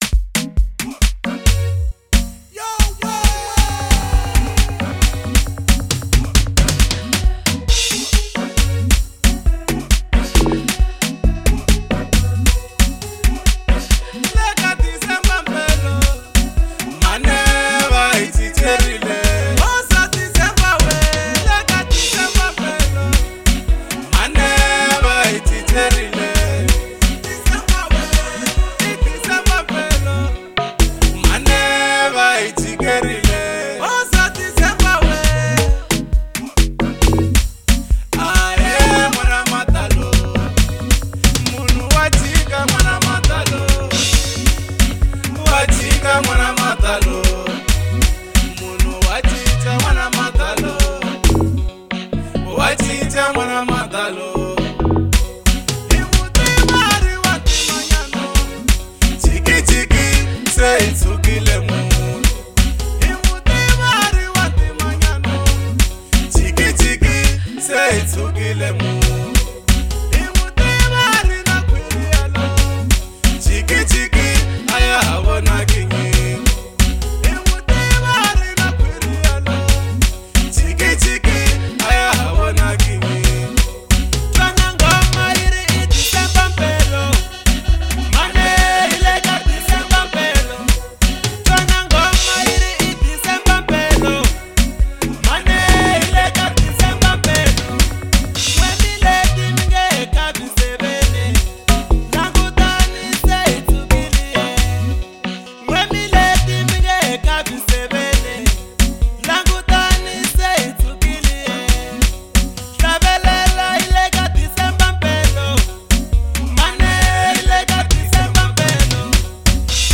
04:43 Genre : Local House Size